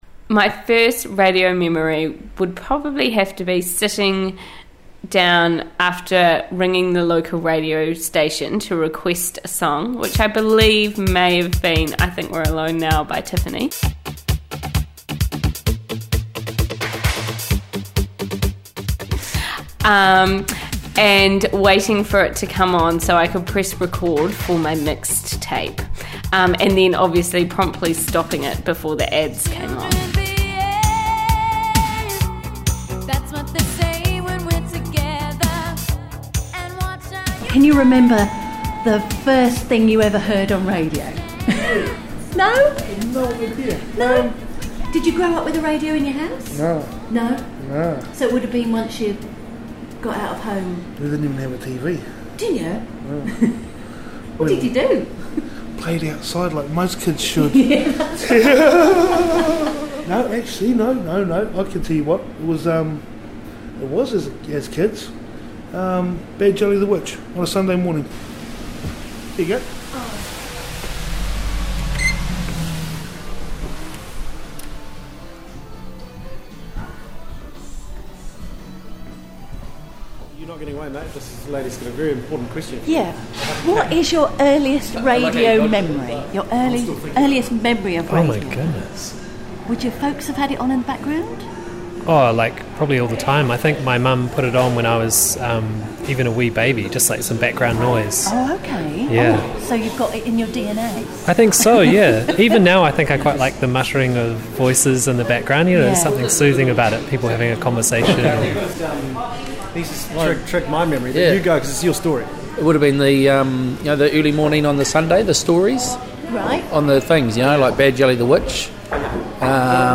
Quick chats with the good people of Martinborough, New Zealand, about their earliest audio and radio memories.
I took a number of folk by surprise in Martinborough the other day and asked them to recall their earliest radio memory.
final-mboro-vox-pops.mp3